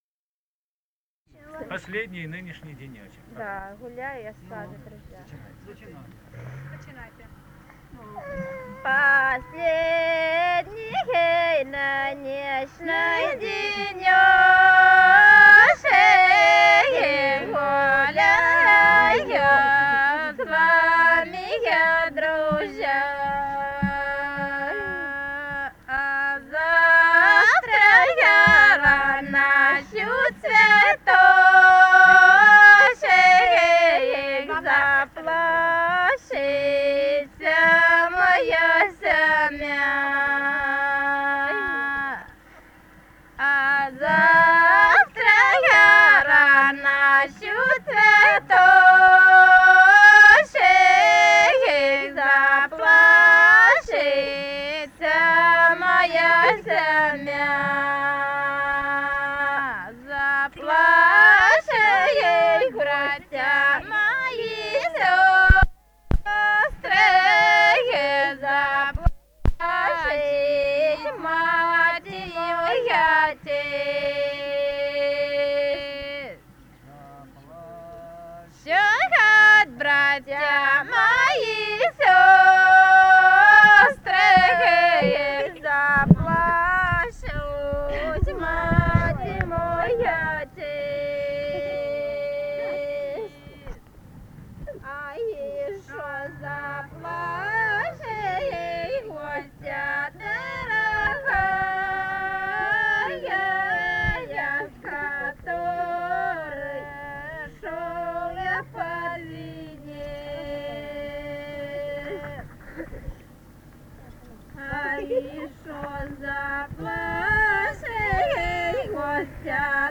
Этномузыкологические исследования и полевые материалы
Ставропольский край, с. Бургун-Маджары Левокумского района, 1963 г.